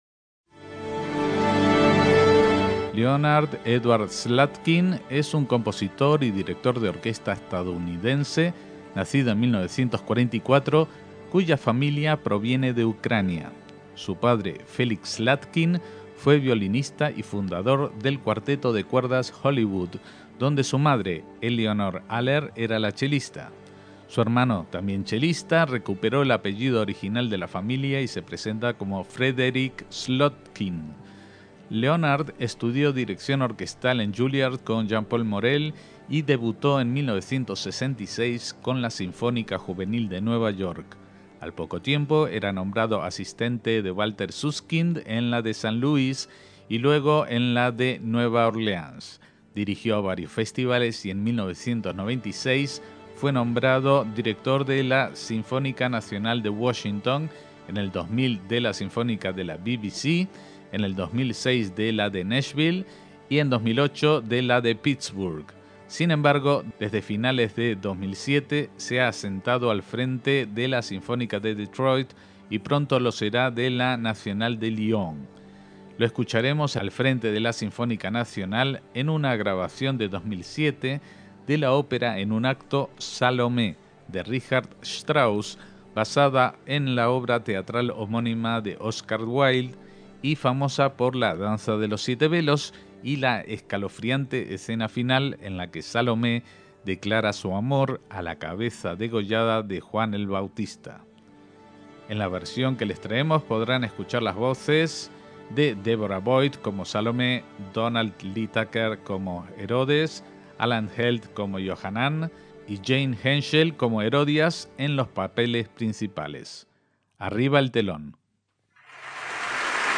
ÓPERA JUDAICA
formación con la que grabó en 2007 la obra que os traemos